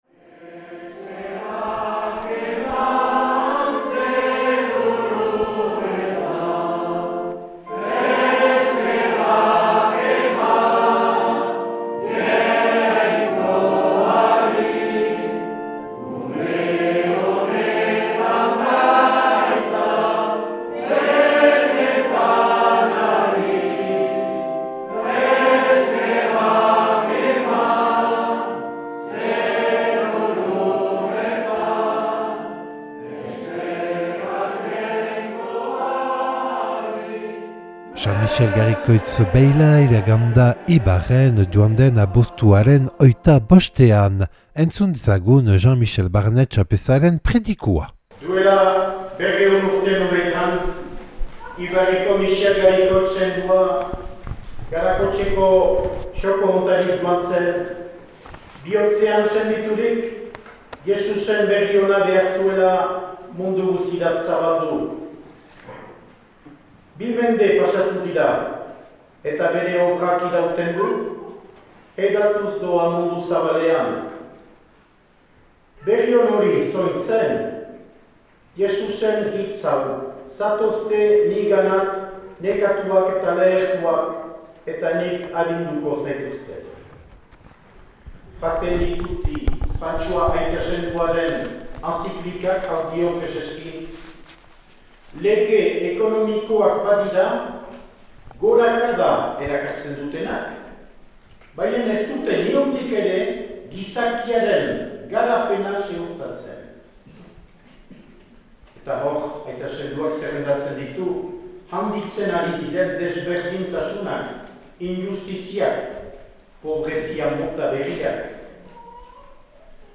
San Mixel Garikoitz beila iragan da 2021. abuztuaren 25ean "Fratelli Tutti" Frantses Aita Sainduaren entziklikaren lemapean.